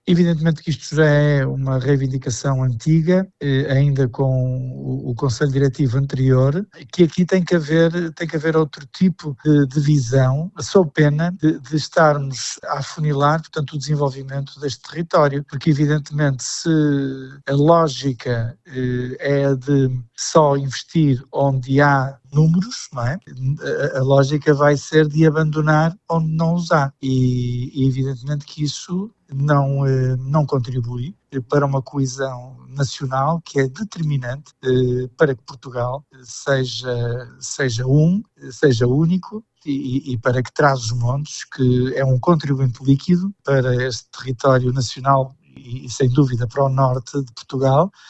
Pedro Lima sublinha que esta é uma reivindicação antiga, defendendo que Trás-os-Montes deve ser olhado de forma diferente do litoral: